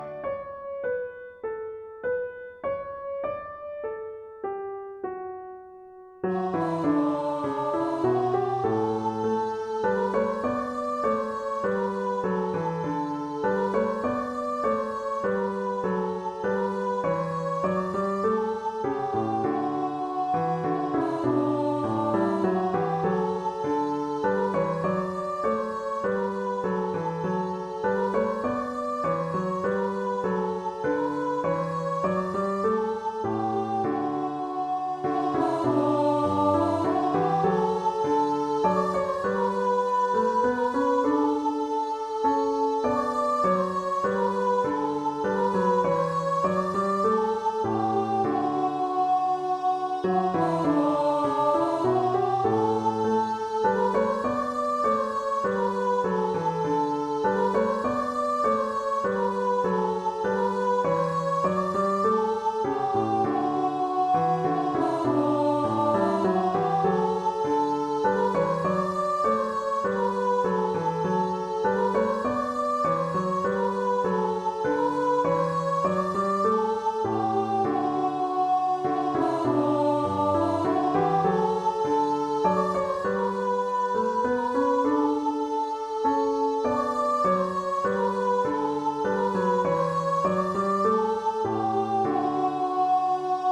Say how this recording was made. Synthesised recording